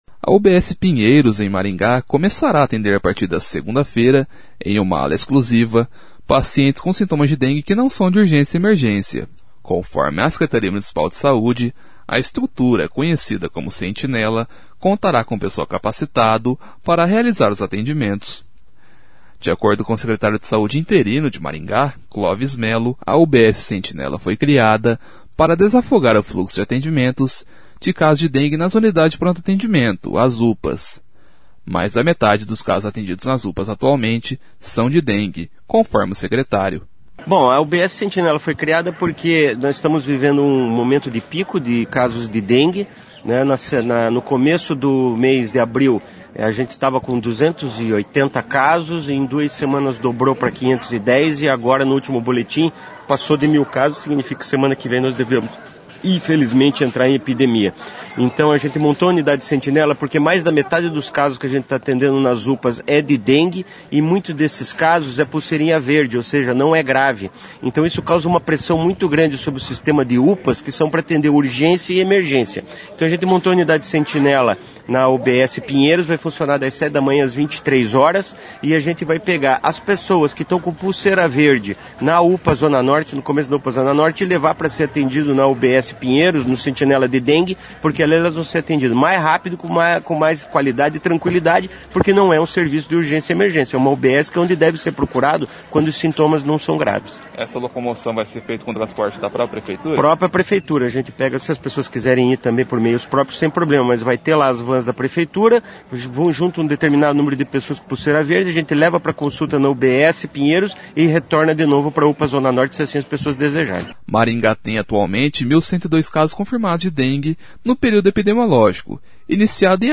Mais da metade dos casos atendidos nas UPAs atualmente são de dengue, conforme o secretário. [ouça no áudio acima]